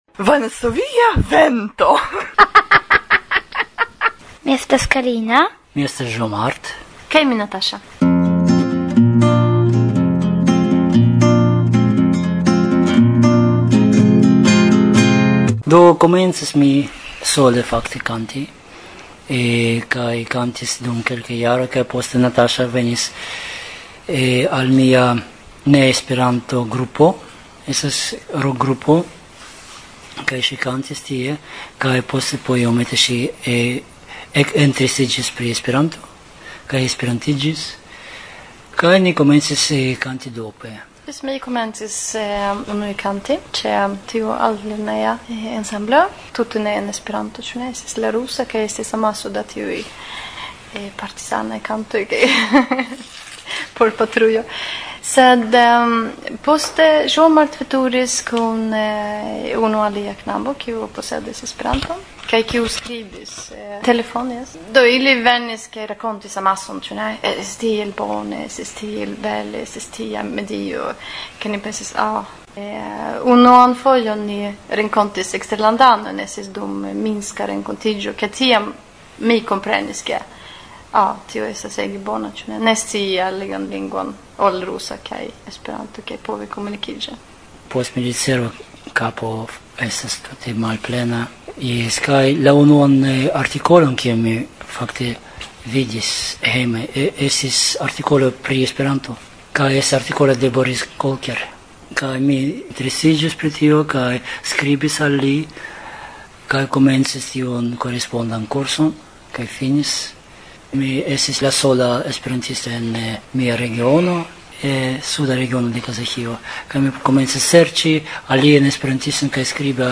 La unua intervjuo…
Realigita aprile de 2006 dum IJF, aperis en la 4a elsendo,